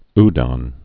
(dŏn)